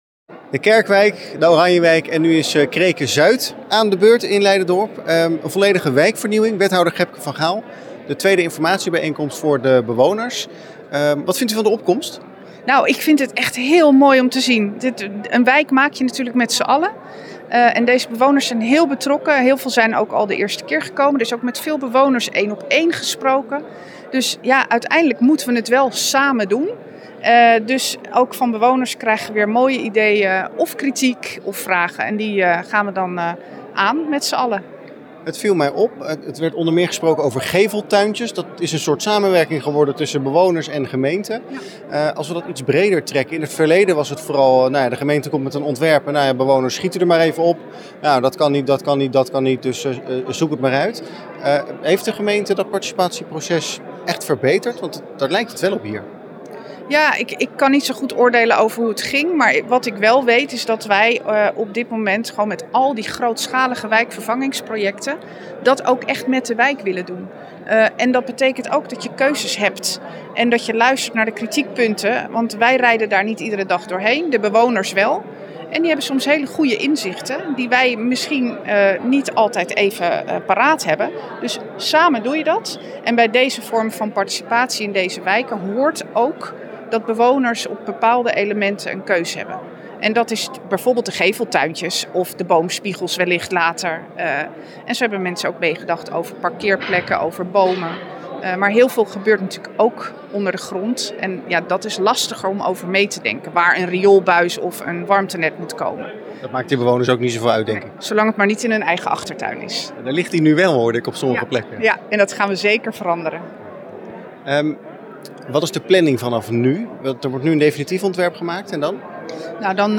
Wethouder Gekbe van Gaal over de herinrichting.
Wethouder-Gebke-van-Gaal-over-herinrichting-Kreken-zuid.mp3